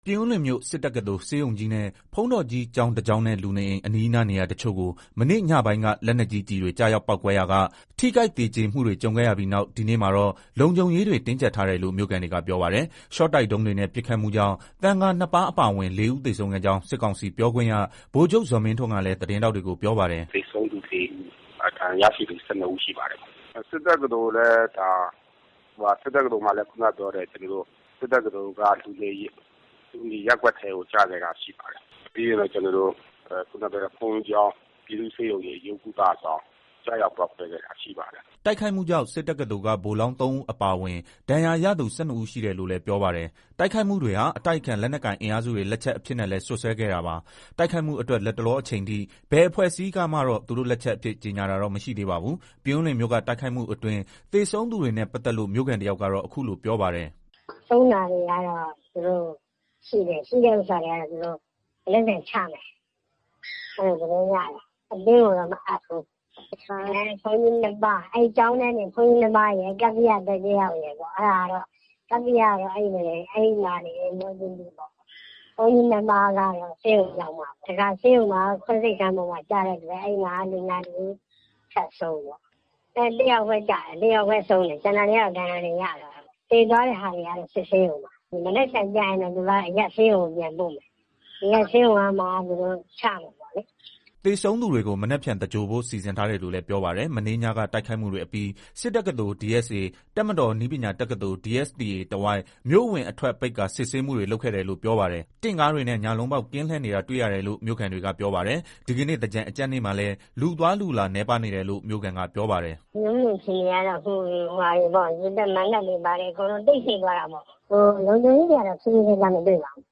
ပြင်ဦးလွင်မြို့က တိုက်ခိုက်မှုအတွင်း သေဆုံးသူတွေနဲ့ ပတ်သက်လို့ မြို့ခံတယောက်ကတော့ အခုလို ပြောပါတယ်။
တိုက်ခိုက်မှု ကြုံခဲ့ရပြီး နောက်တရက်ဖြစ်တဲ့ ဒီကနေ့ မန္တလေးမြို့ အခြေအနေနဲ့ ပတ်သက်လို့ မြို့ခံတယောက်က အခုလိုပြောပါတယ်။